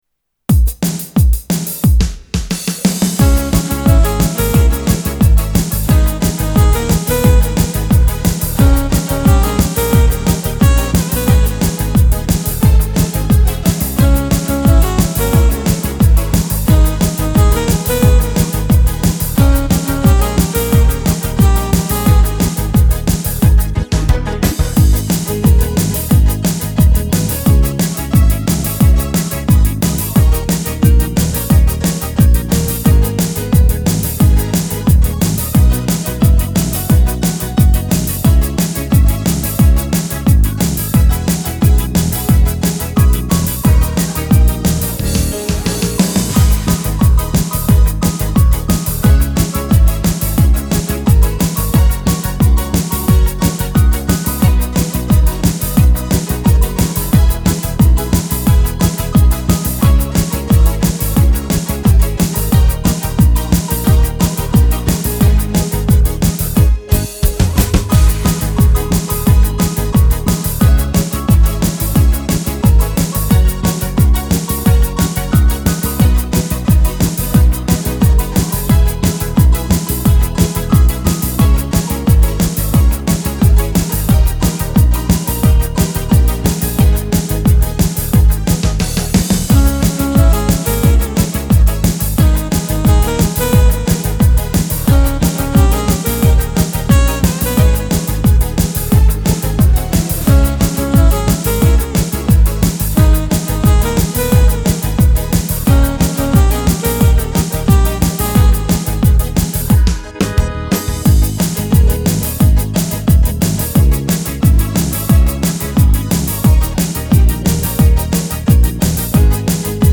инструментал